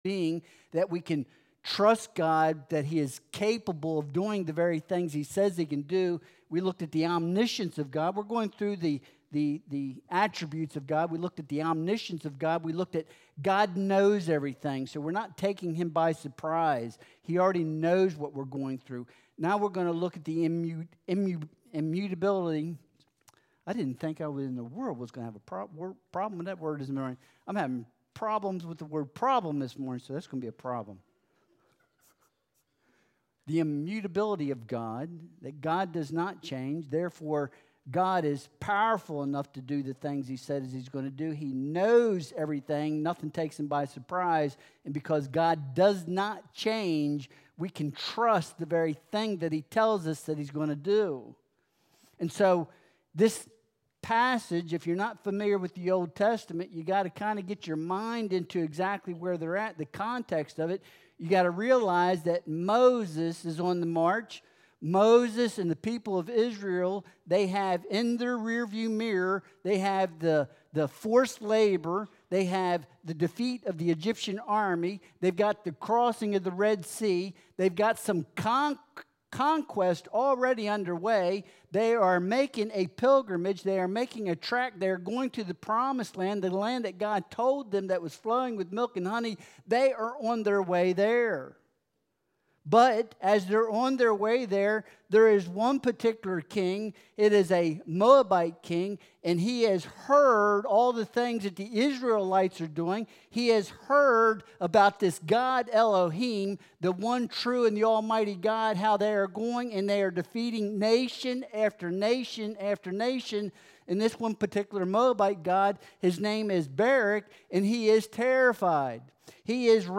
2 Timothy 1.9 Service Type: Sunday Worship Service The Attributes of God